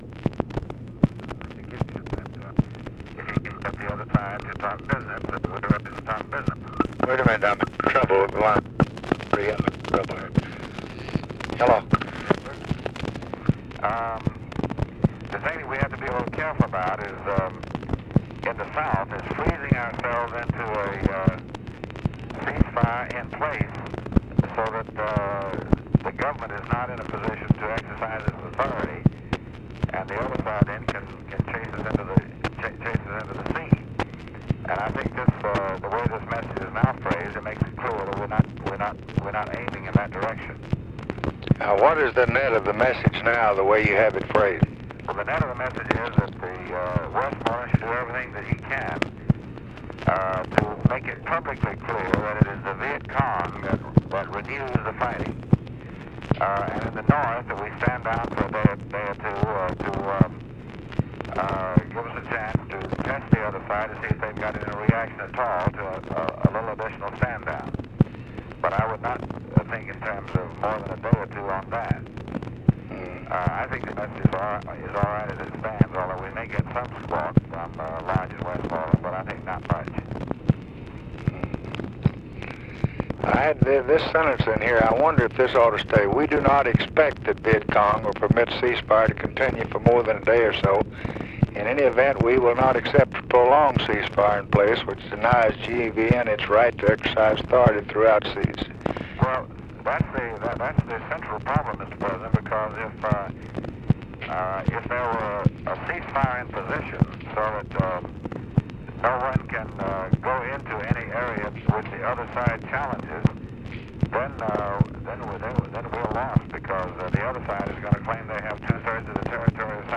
Conversation with DEAN RUSK, December 24, 1965
Secret White House Tapes